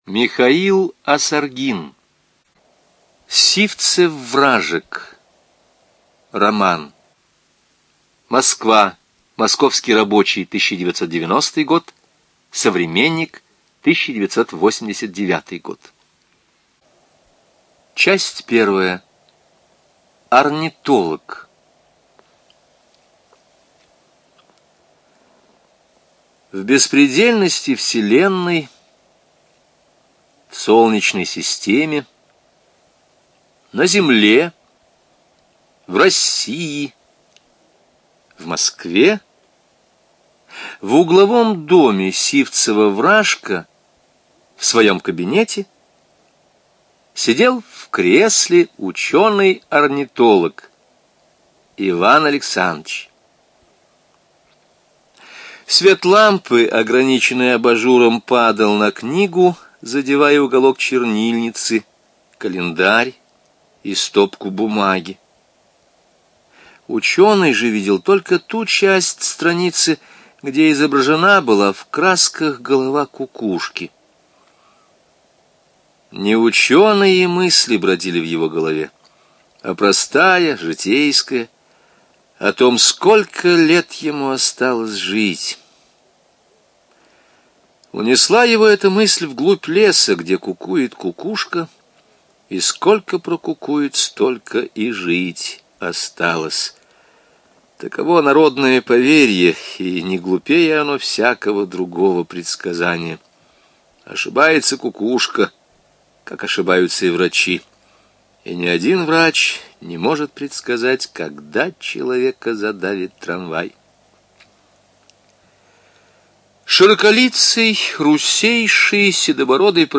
Студия звукозаписиЛогосвос